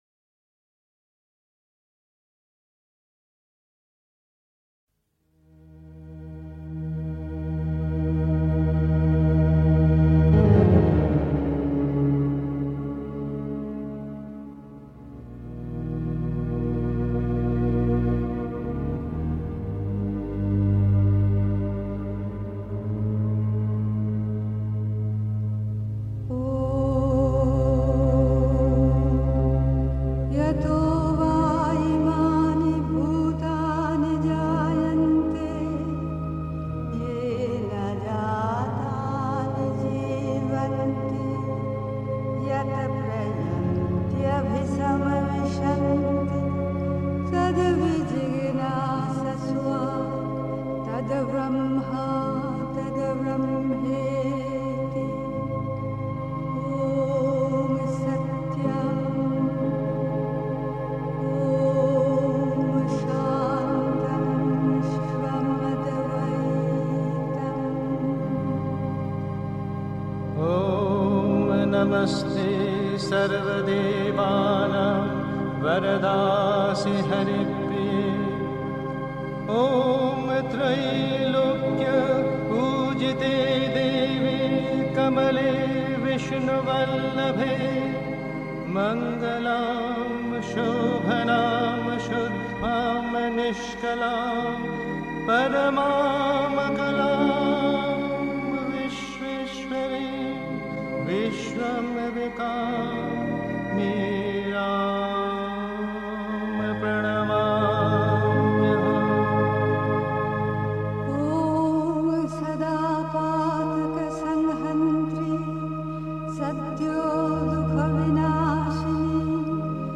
Pondicherry. 2. Bemühung ist unerlässlich (Sri Aurobindo, CWSA Vol 29, pp. 83-84) 3. Zwölf Minuten Stille.